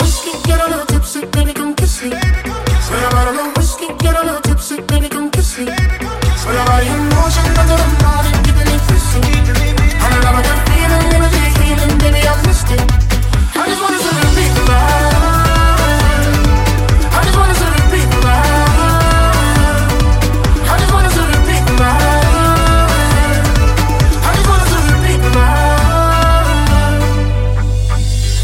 Genere: pop, slap, deep, house, edm, remix